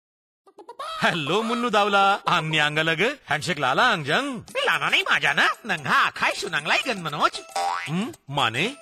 This is second in the series of five Radio PSA, address backyard farmers and their families. It uses a performer and a rooster puppet as a creative medium to alert families to poultry diseases and instill safe poultry behaviours.
Radio PSA